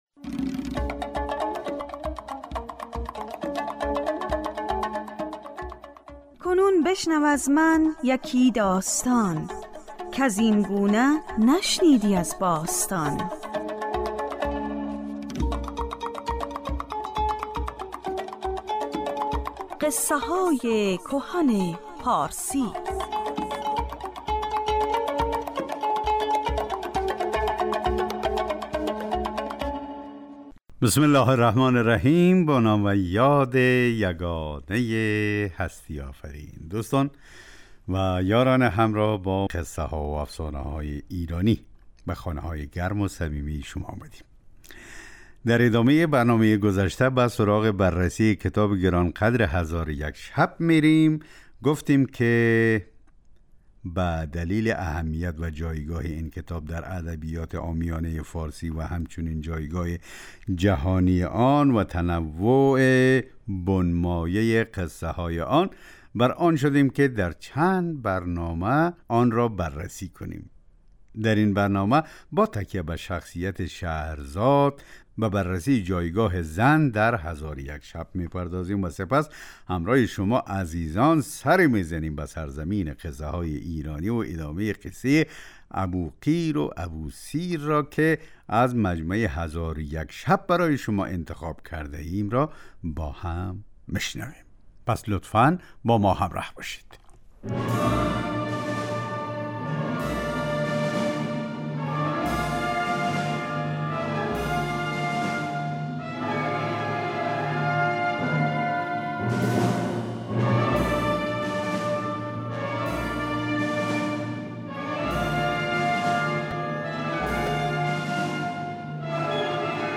برنامه قصه های کهن پارسی جمعه ها ساعت 12:40 دقیقه به وقت ایران پخش می شود. در بخش اول این برنامه به ادبیات پارسی پرداخته می شود و در بخش دوم یکی از داستان های هزار و یک شب روایت می شود.